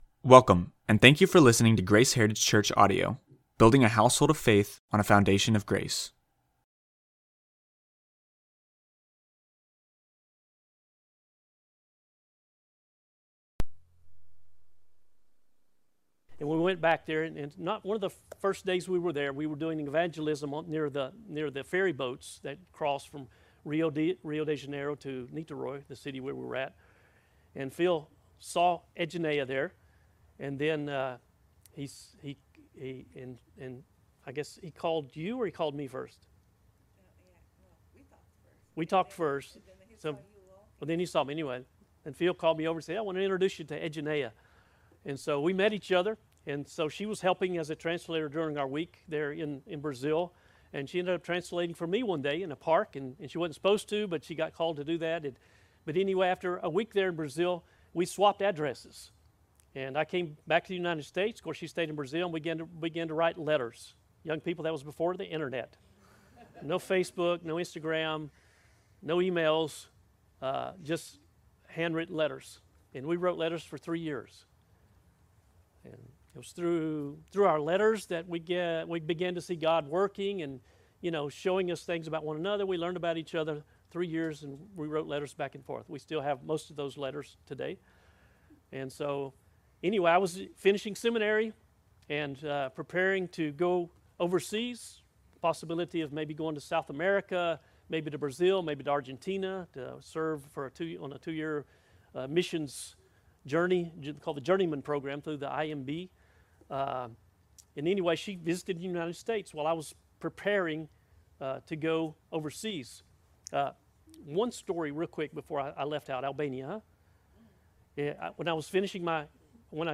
Not all of the audio for this video was properly recorded, unfortunately we can only offer the second half of this bible study.
Tagged with Bible Study Audio (MP3) Previous Loving Community and our Language Next Loving Community and Our Anger 0 Comments Add a Comment Cancel Your email address will not be published.